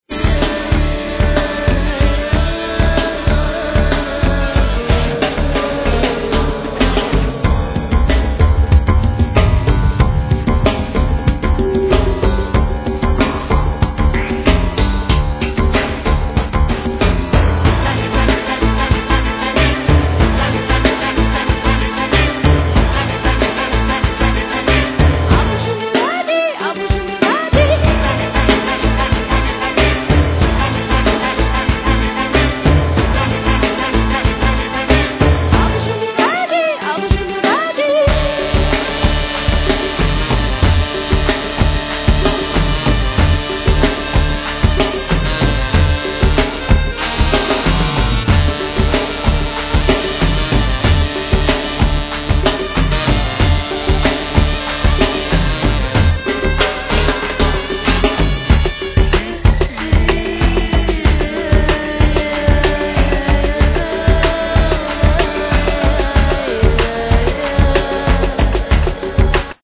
Vocals,Bendir,Spanish claps
Cello,Violin